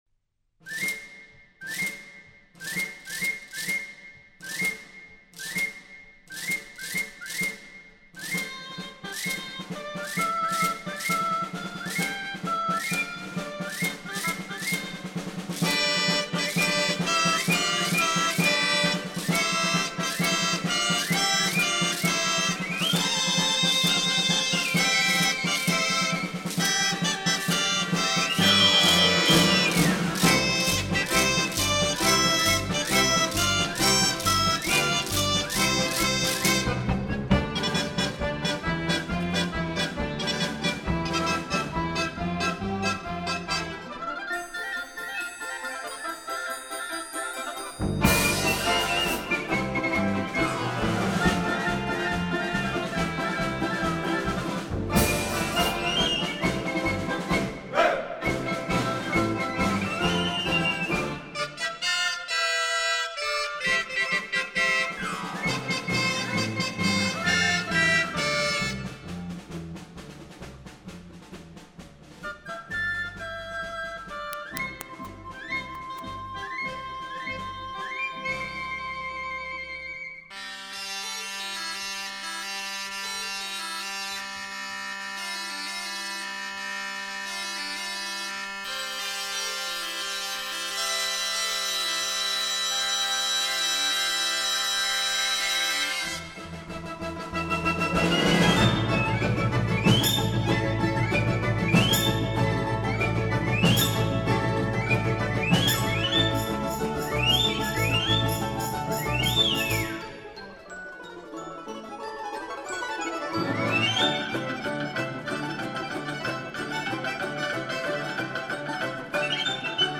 Russian Folk Instruments Soloist's Band